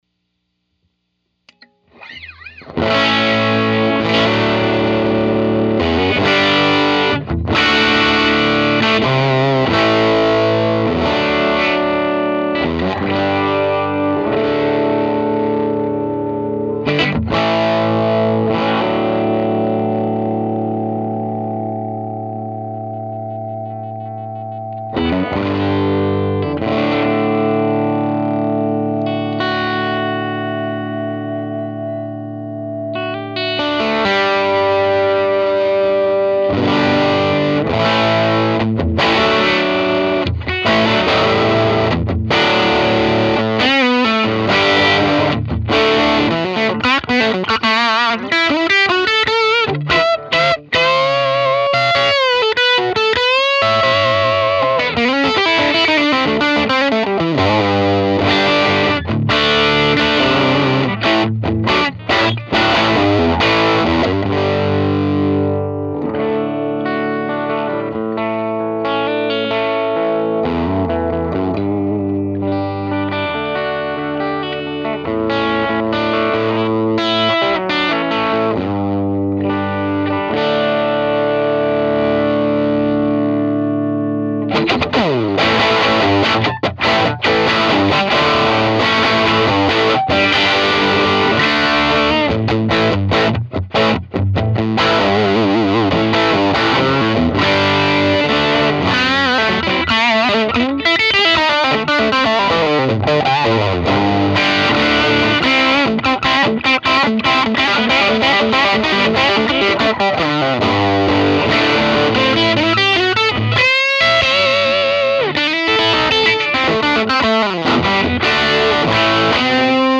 These amps usually break into pretty good power tube distortion as you turn them up.
Medium SG
Trinity_deluxe_med_gain_sg.mp3